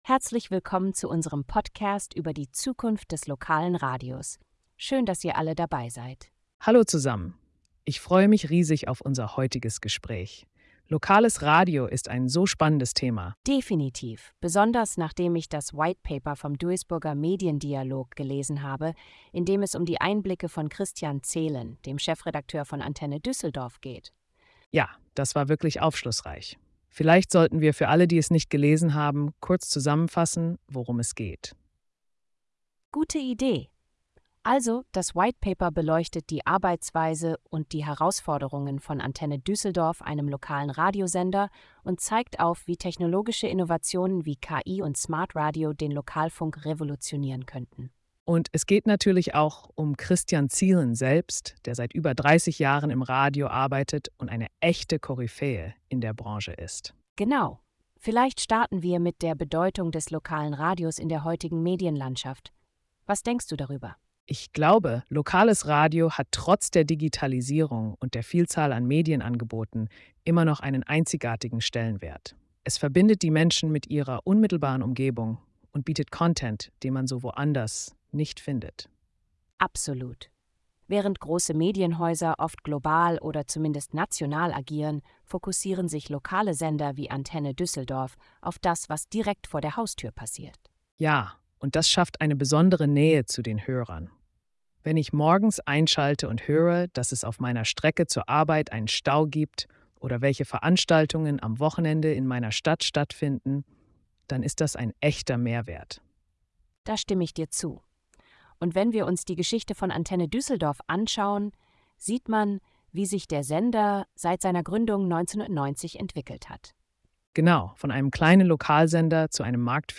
Unsere KI-Hosts besprechen, wie lokales Radio technologische und inhaltliche Entwicklungen aufgreift.